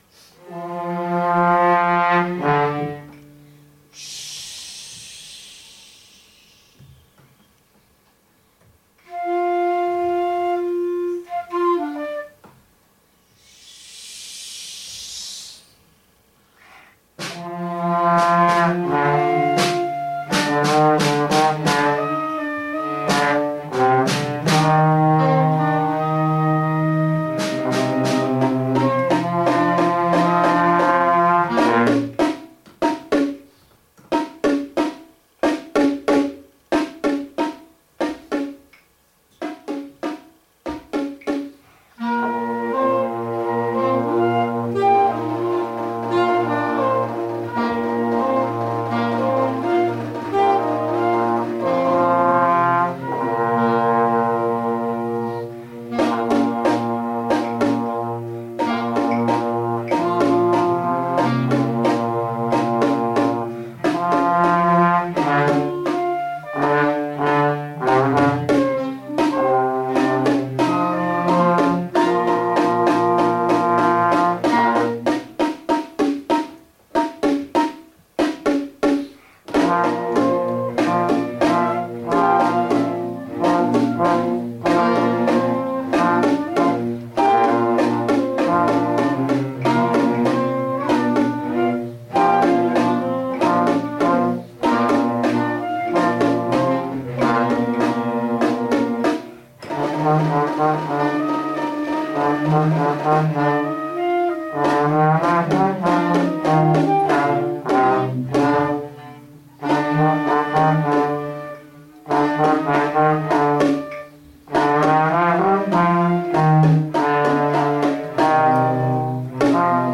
Concierto de verano de 2019